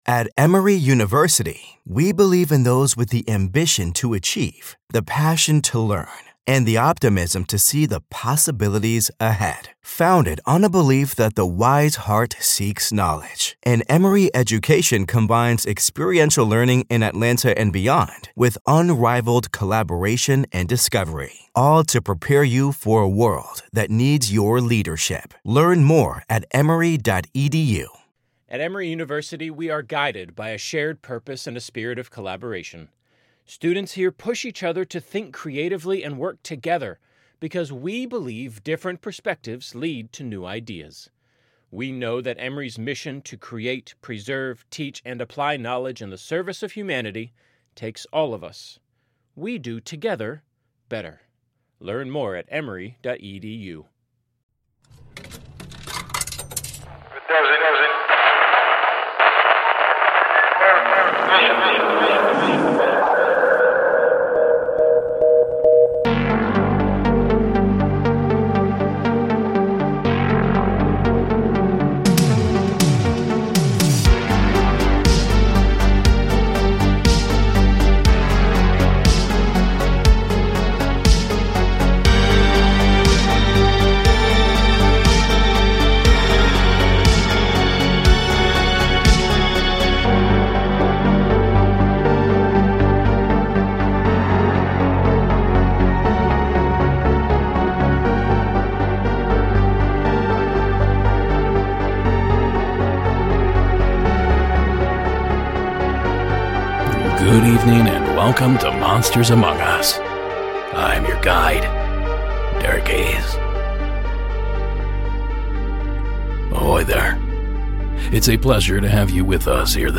Season 14 Episode 3 of Monsters Among Us Podcast, true paranormal stories of ghosts, cryptids, UFOs and more told by the witnesses themselves.